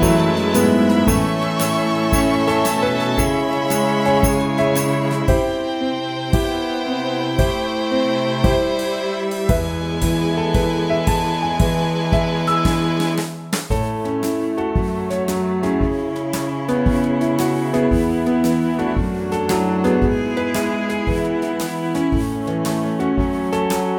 Up 2 Semitones For Female